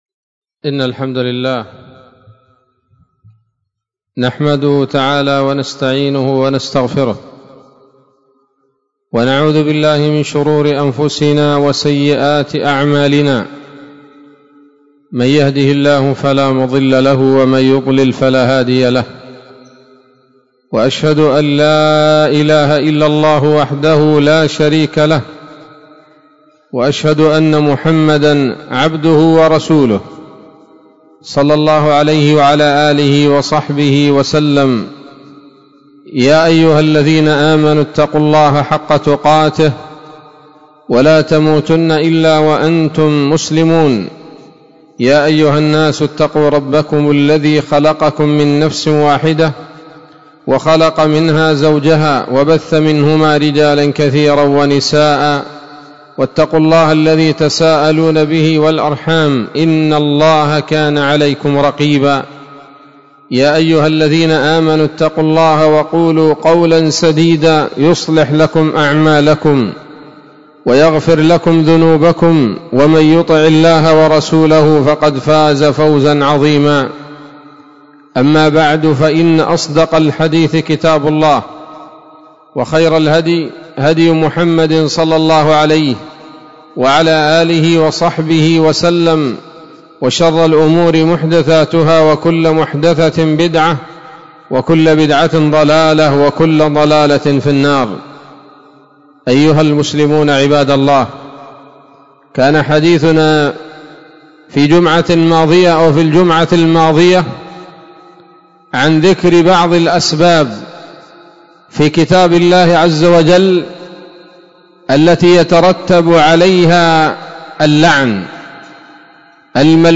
خطبة جمعة بعنوان
20 جمادى الآخرة 1444 هـ، دار الحديث السلفية بصلاح الدين